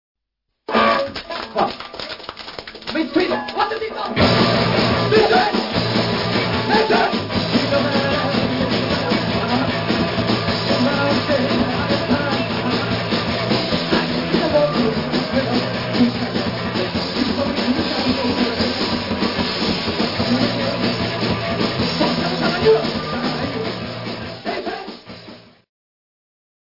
京都サーカス＆サーカス Jun.1979